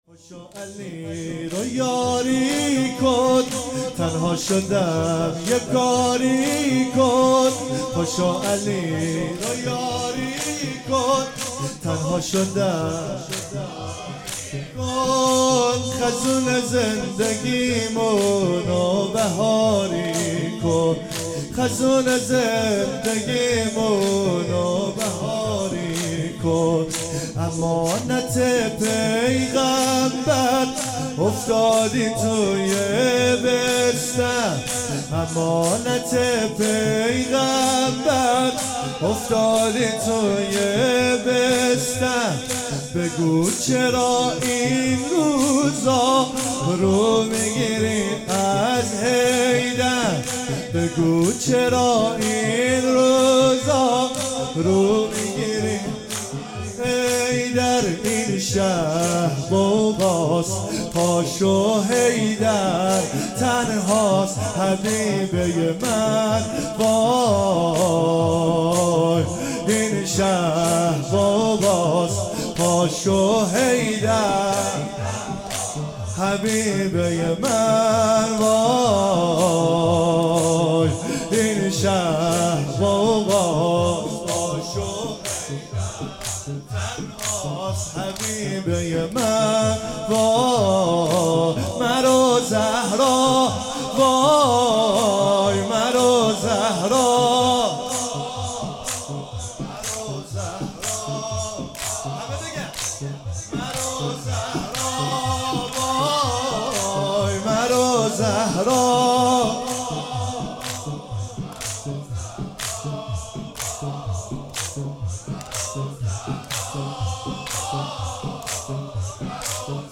شب سوم فاطمیه 96 - زمینه